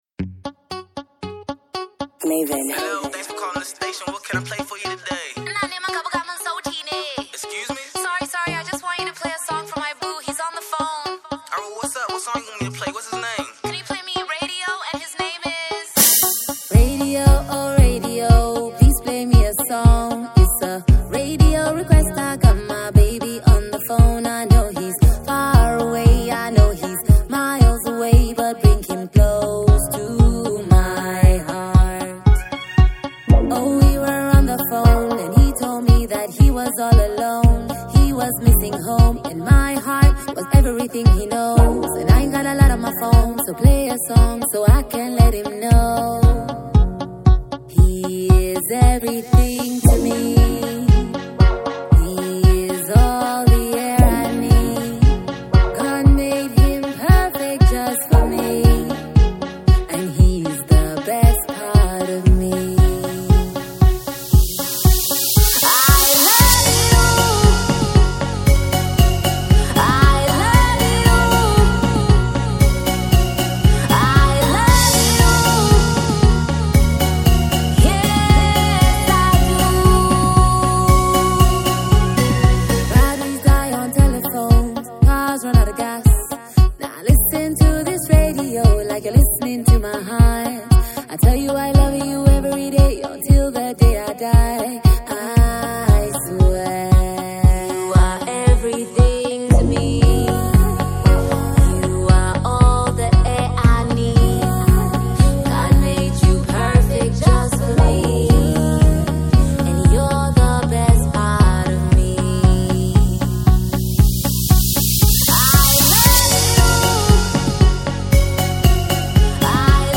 Alternate Version